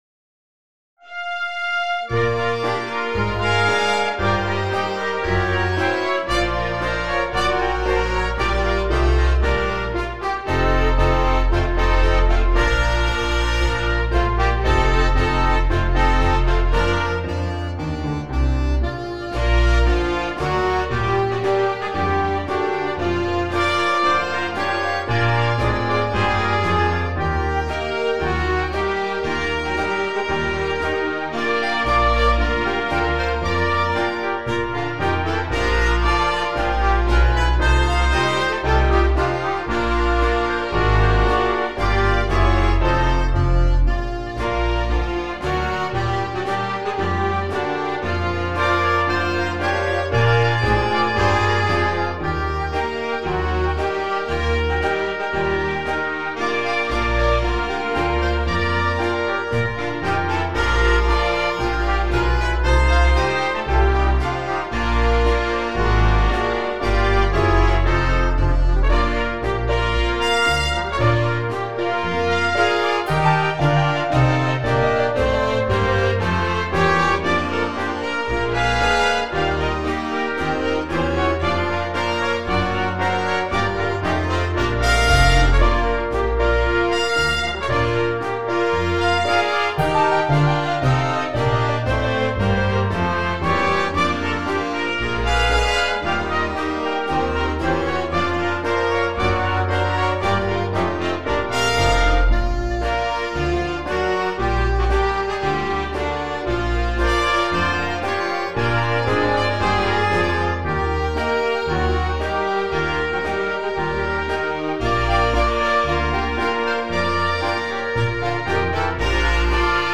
Early jazz orchestra